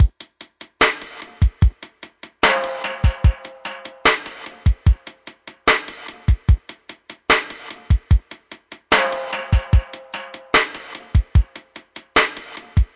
DM75鼓的投放
描述：DuB HiM丛林onedrop rasta Rasta雷鬼雷鬼根源根
Tag: onedrop 丛林 配音 雷鬼 拉斯特 拉斯塔 瑞格